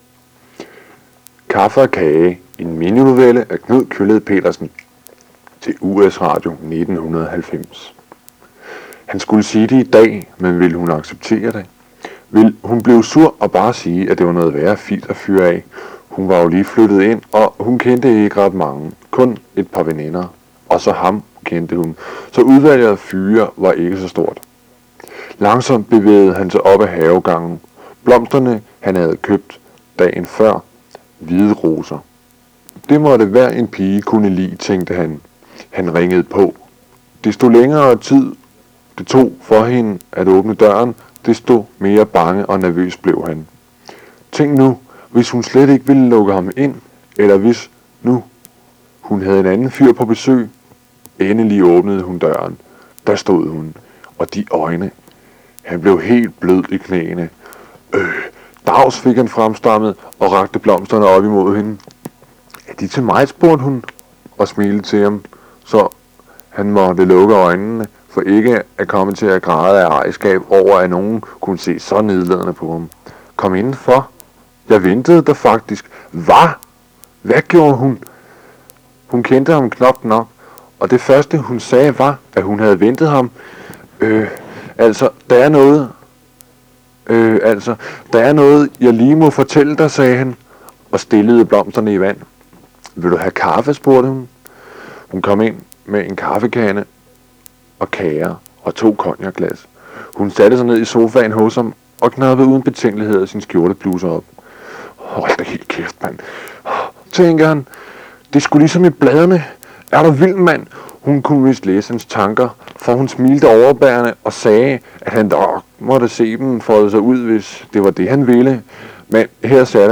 1990 us radio, mininovelle kaffe og kage.wav